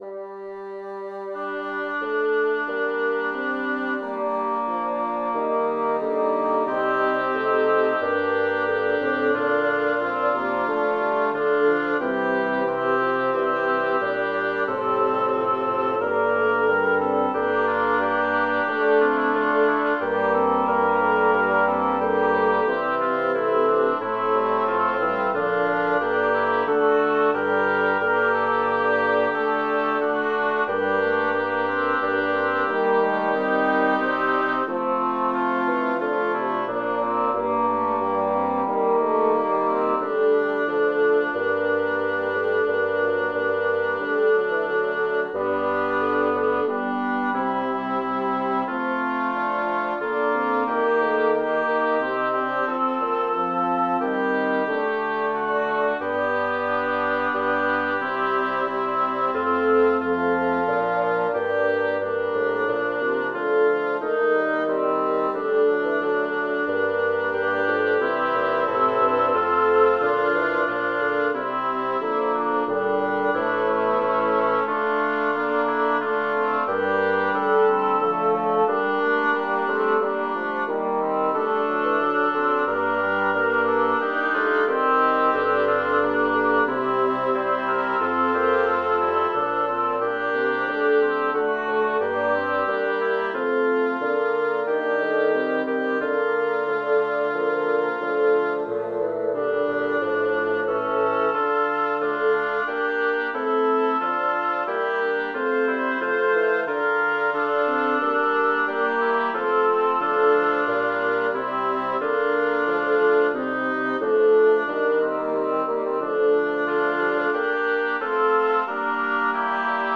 Title: Simile est regnum caelorum grano sinapis Composer: Claudio Merulo Lyricist: Number of voices: 6vv Voicings: SAATTB, STTTTB or STTBBB Genre: Sacred, Motet
Language: Latin Instruments: A cappella